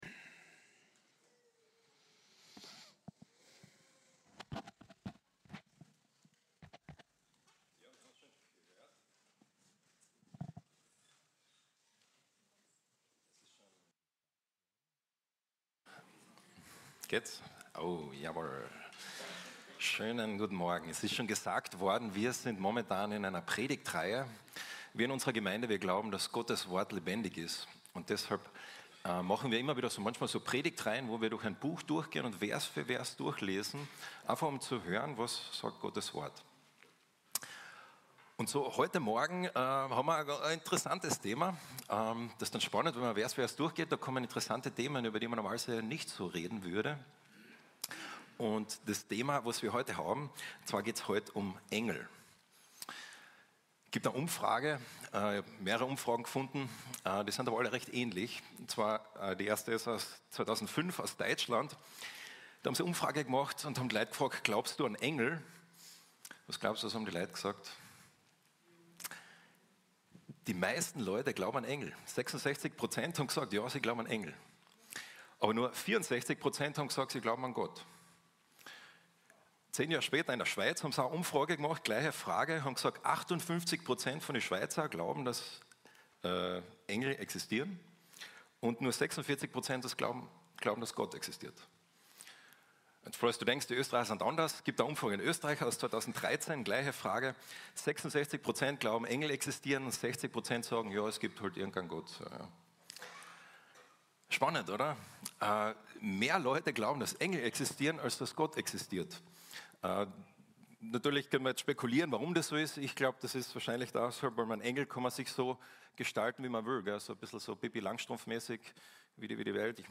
Predigten – Archiv – FEG Klagenfurt